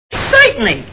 Three Stooges Movie Sound Bites